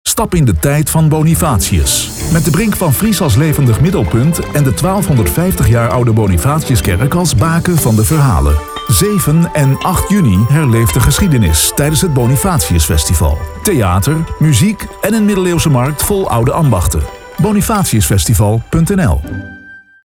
Commercial Bonifatius Festival Vries 2025 - RTV Drenthe.mp3